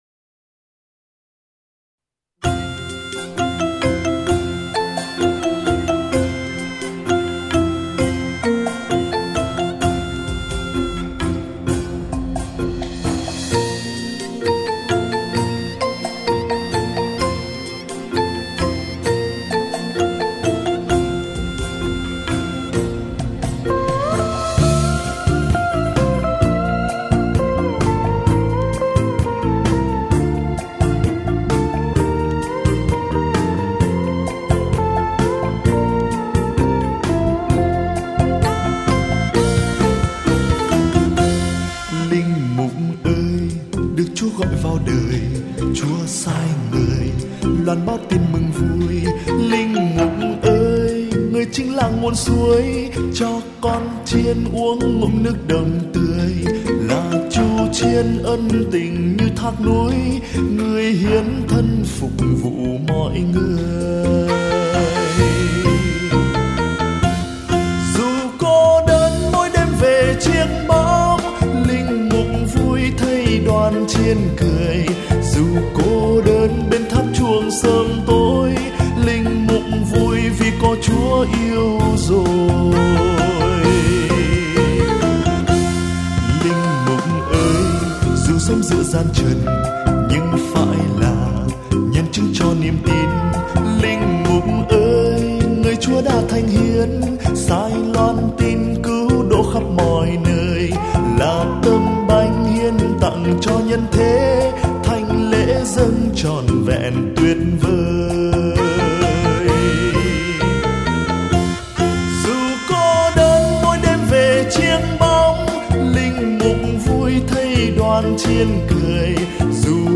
ca khúc mang âm hưởng nhạc tây nguyên.